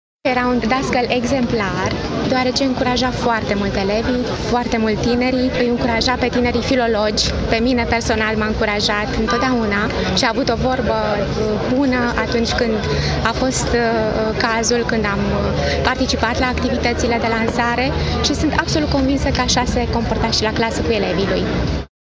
evocată la Târgul de Carte Gaudeamus Radio România